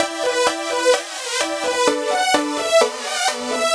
Index of /musicradar/french-house-chillout-samples/128bpm/Instruments
FHC_Pad B_128-E.wav